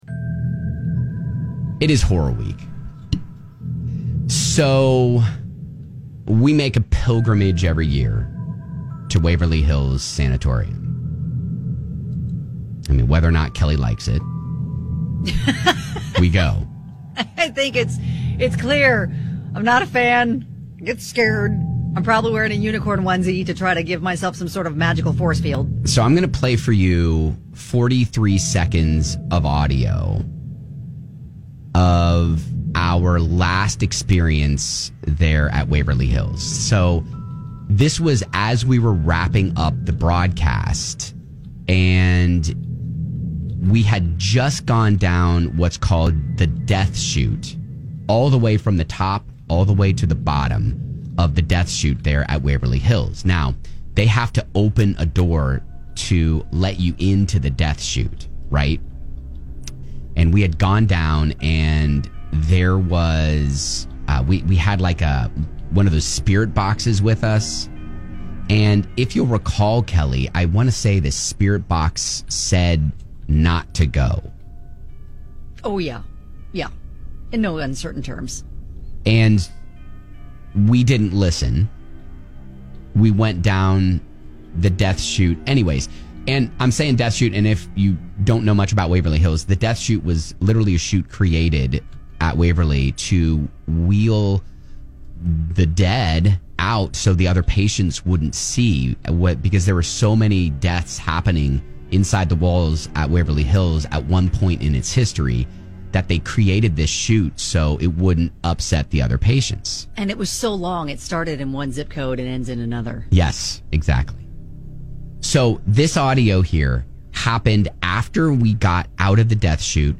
Back in 2018 when we went to Waverly Hills, we caught some audio just outside the "death chute" as we were getting ready to wrap up.... it's shocking!